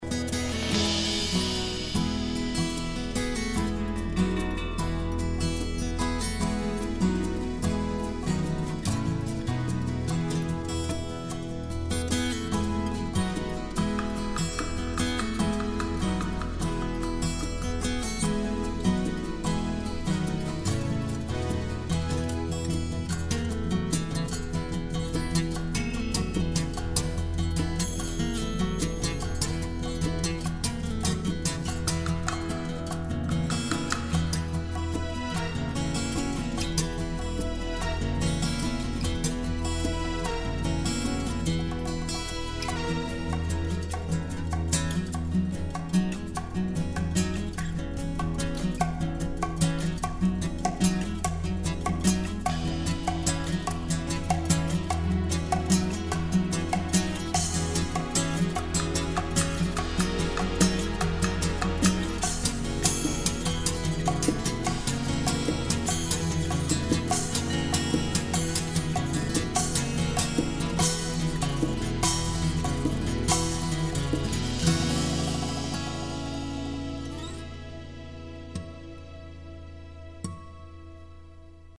Acoustic guitar
Acoustic guitar & violin
Keyboards
Percussion
Shakuhachi & tablas